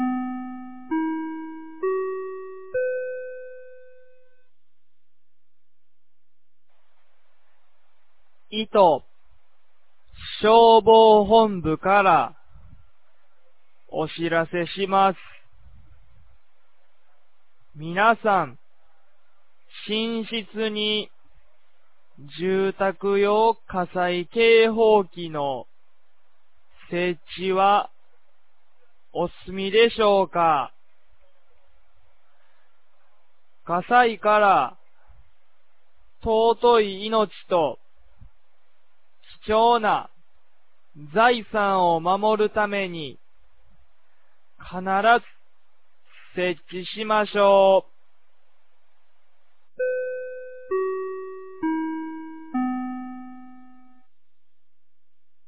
2024年09月30日 10時01分に、九度山町より全地区へ放送がありました。
放送音声